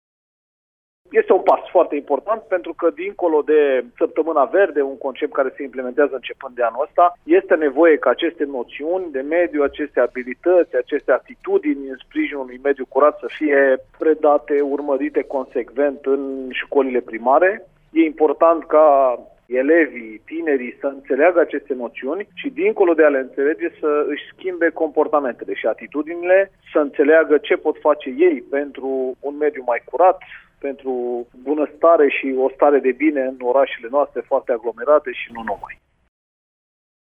Secretarul de stat la Ministerul Mediului, Apelor și Pădurilor, Ionuț Sorin Banciu: